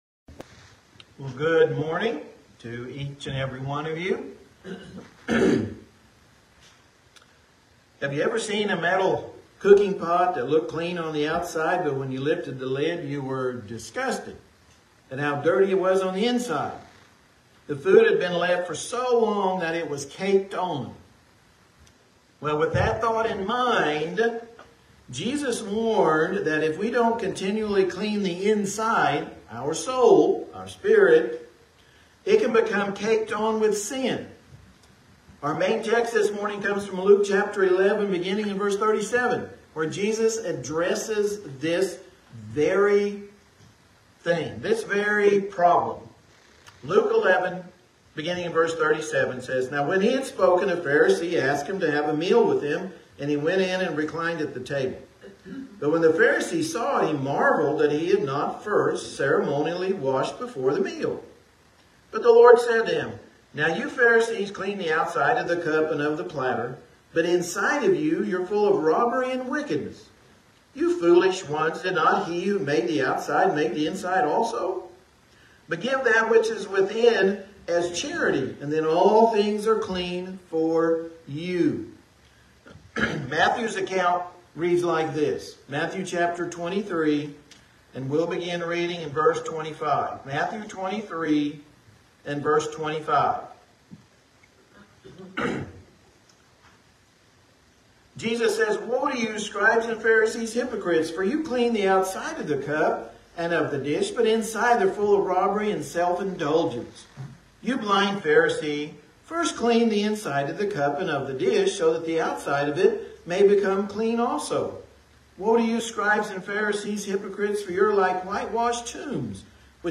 Church of Christ - Cleaning the Inside - Sermon on Inner Purity